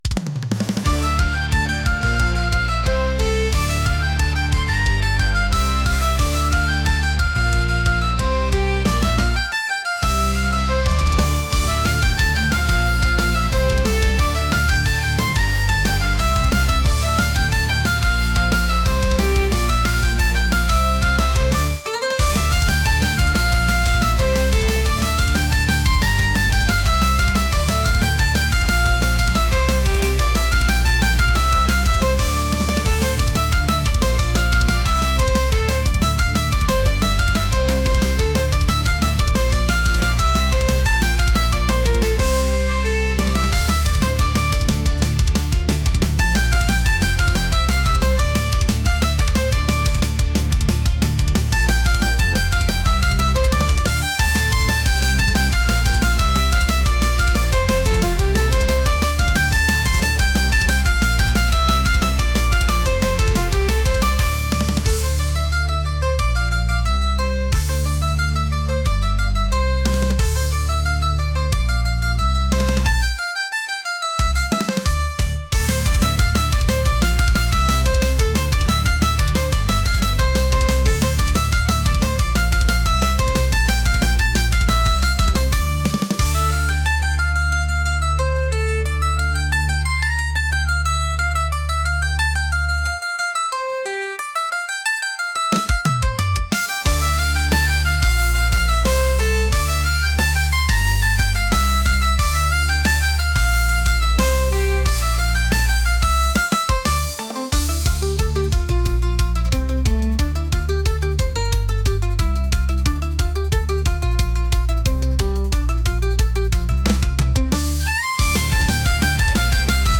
energetic | folk | metal